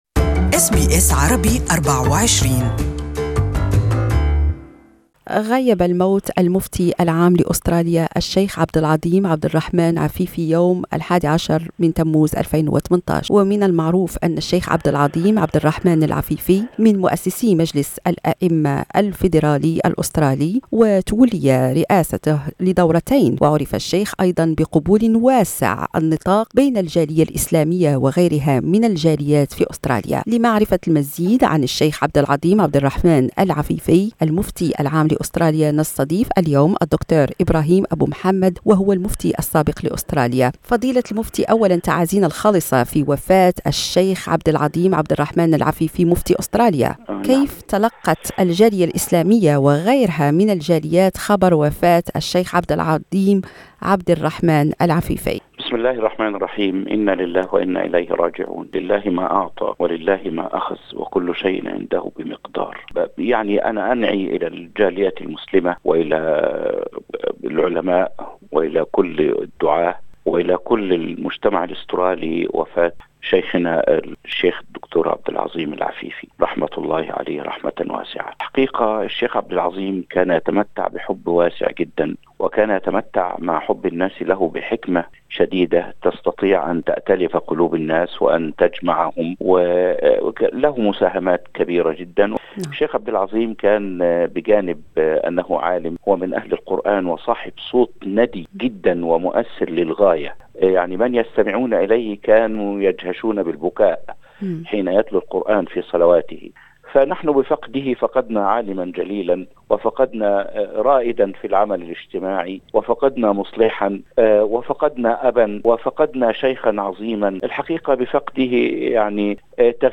المفتي السابق لأستراليا الدكتور ابراهيم أبو محمد يتحدث عن المفتي الشيخ عبدالعظيم عبد الرحمن العفيفي.
استمعوا الى تفاصيل اللقاء في المقطع الصوتي أعلاه.